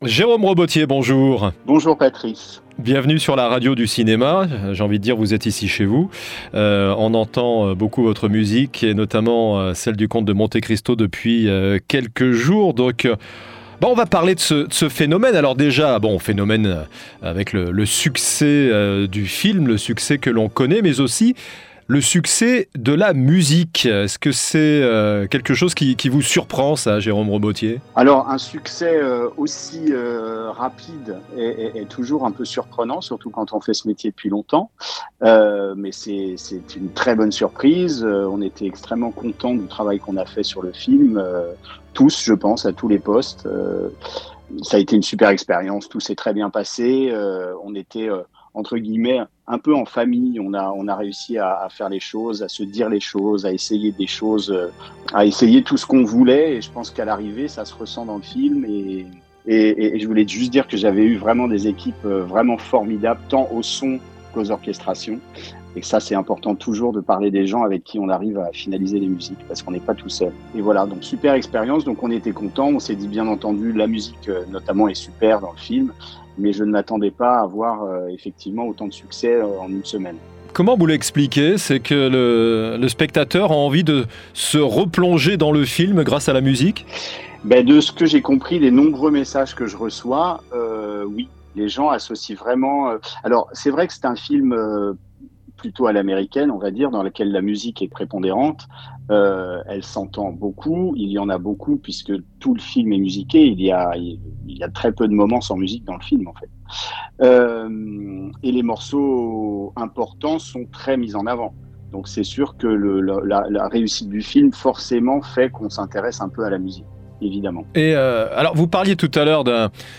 %%Les podcasts, interviews, critiques, chroniques de la RADIO DU CINEMA%% La Radio du Cinéma